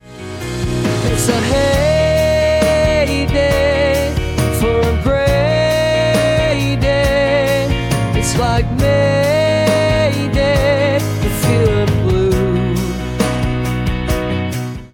Bei der vorliegenden Weihnachts-Power-Ballade war es zunächst nur die Idee für den Refrain.
Udio ist insgesamt „more raw“.
Wir haben eine Hookline.